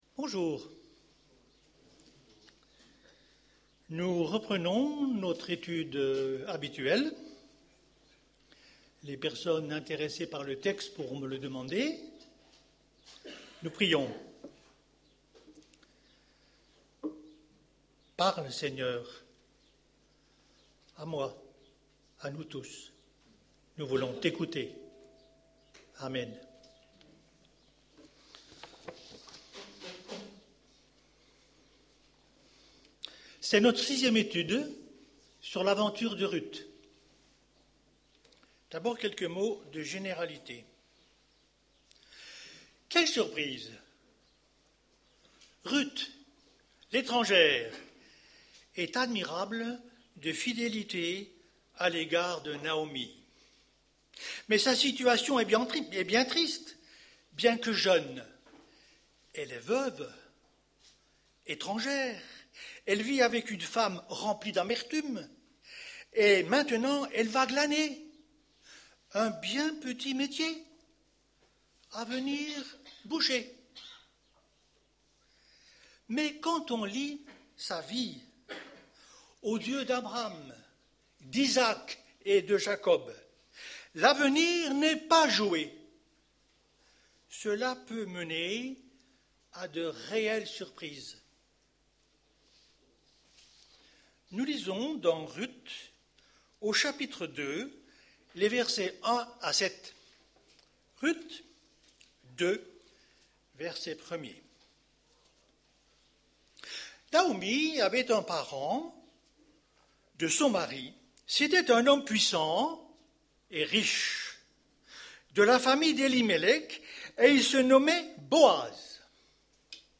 Culte du 22 janvier